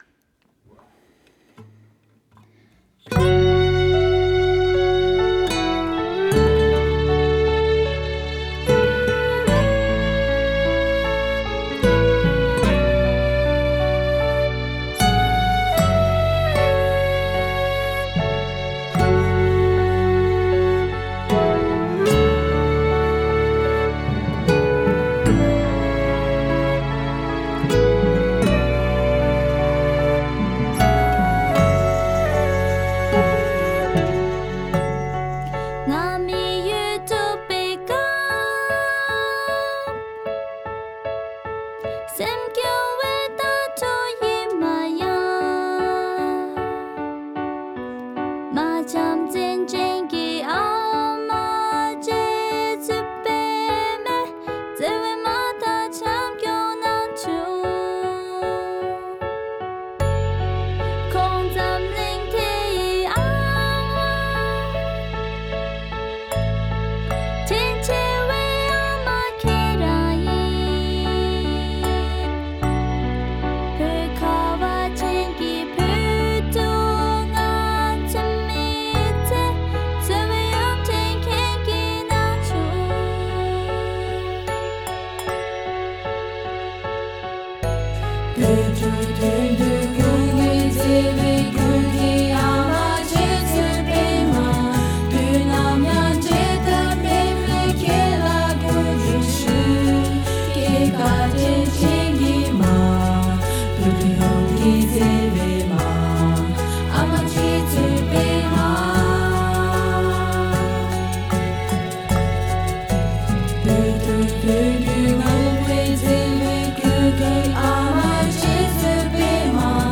A Tibetan song dedicated to the Dalai Lama’s sister
Flute
Bass